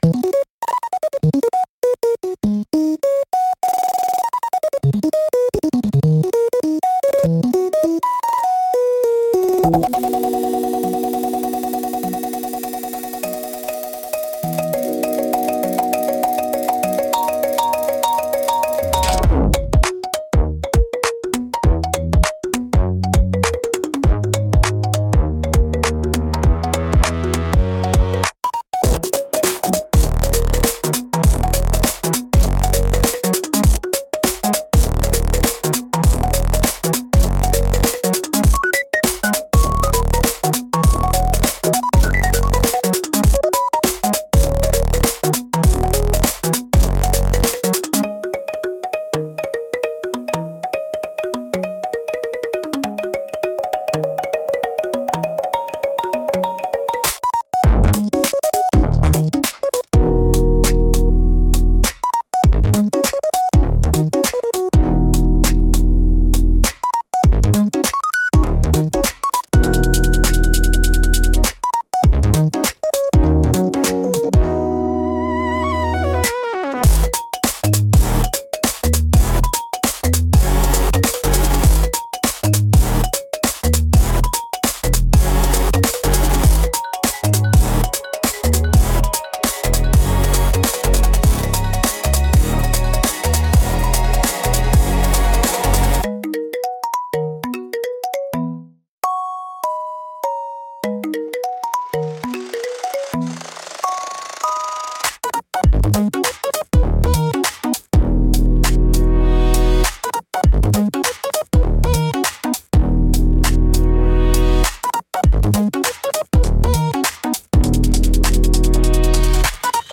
聴く人の感覚を刺激し、緊張感や好奇心を喚起しながら、独自の雰囲気を強調する効果があります。